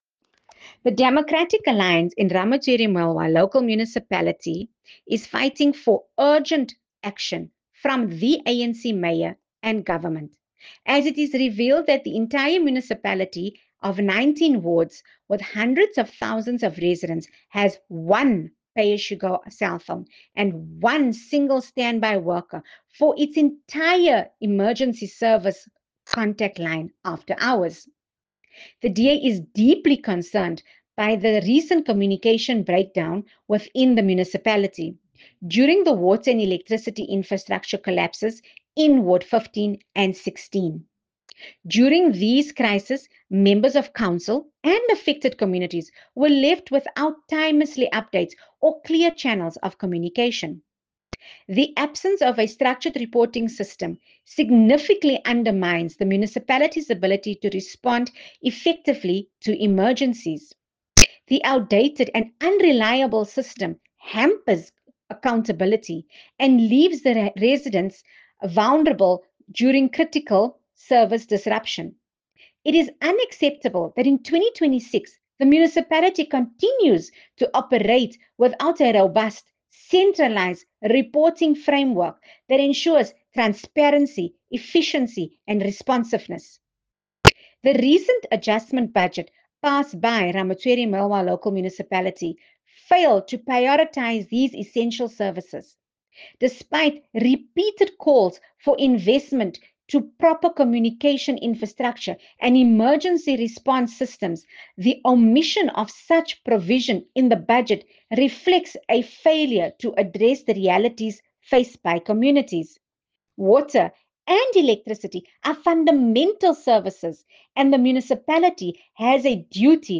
Issued by Imaan Sayed Suliman – DA PR Councillor, Ramotshere Moiloa Local Municipality
Note to Broadcasters: Please find attached soundbites in
English by Imaan Sayed Suliman